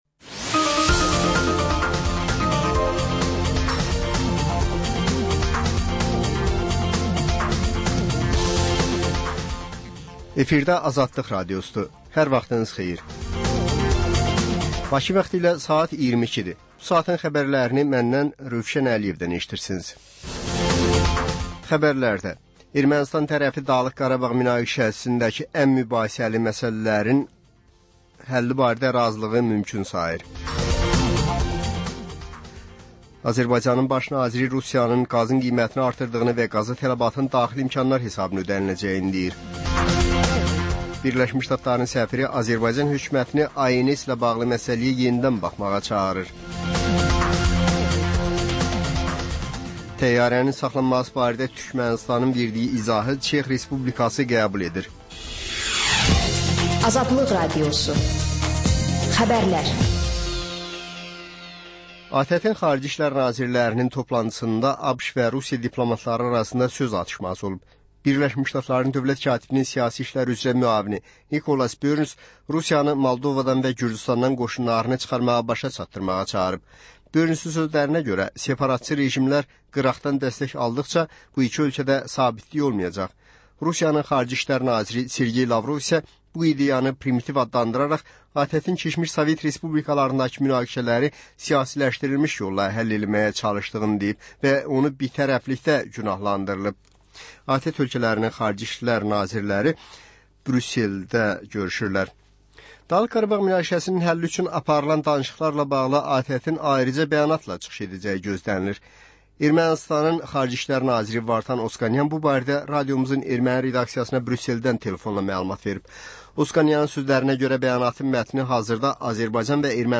Xəbərlər, reportajlar, müsahibələr. Və sonda: Azərbaycan Şəkilləri: Rayonlardan reportajlar.